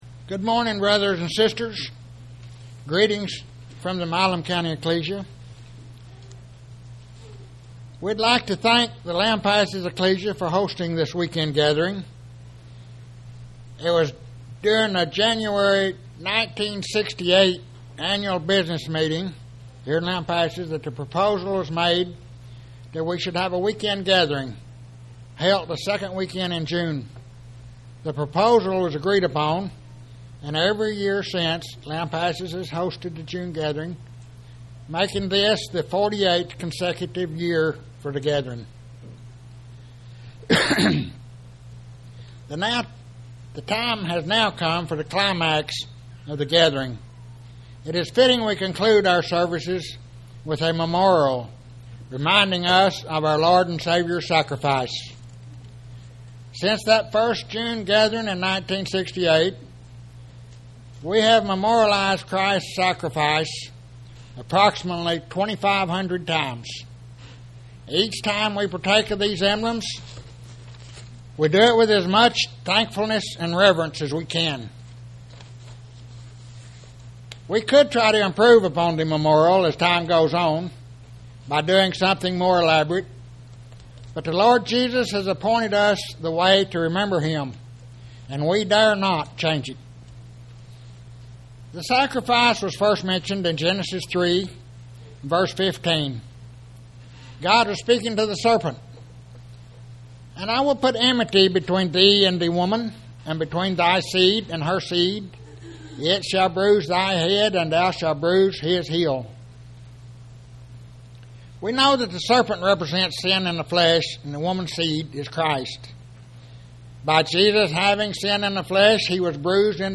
The Lampasas Ecclesia held a gathering in June 2015.